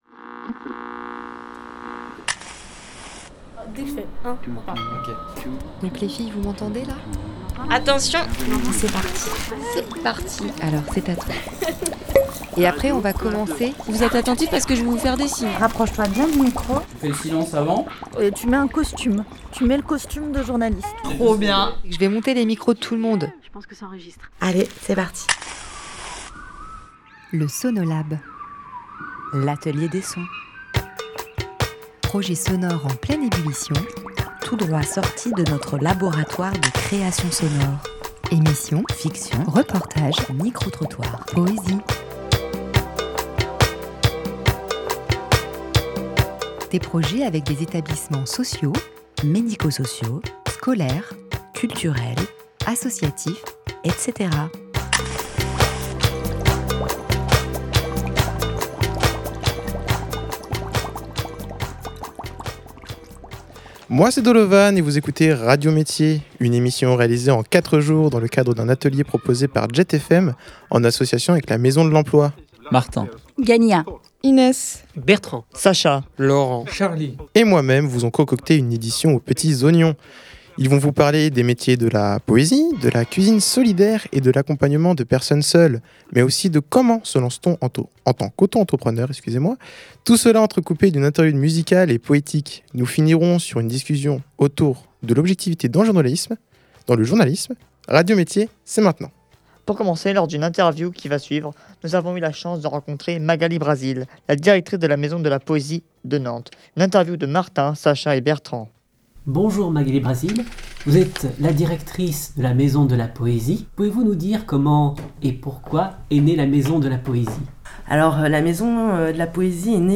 Leur défi ? Réaliser de A à Z des reportages et une émission dédiée à des métiers ou univers professionnels qui les intriguent.